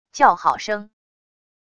叫好声wav音频